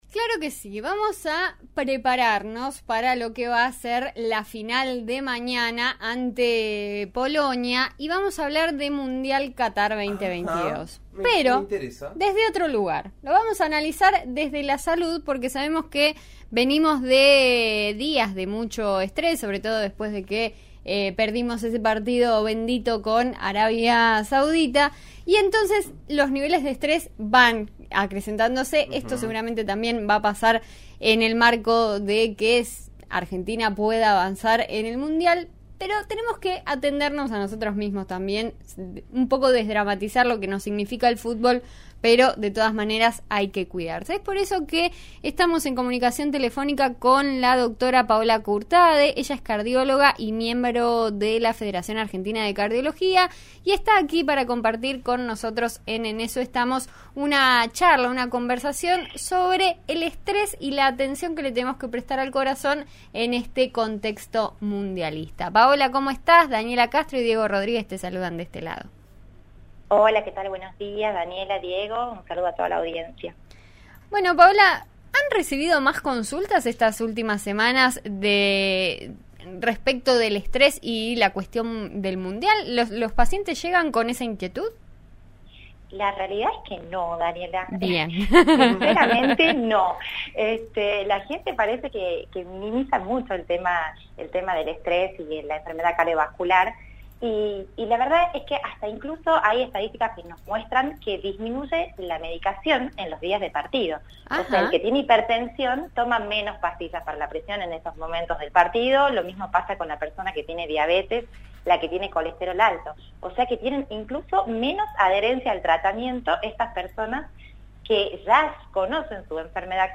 médica cardióloga y parte de la Federación Argentina de Cardiología
dialogó con «En Eso Estamos» de RÍO NEGRO RADIO y dejó algunas propuestas para controlar el estrés y el corazón.